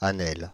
Asnelles (French pronunciation: [anɛl]
Fr-Asnelles.ogg.mp3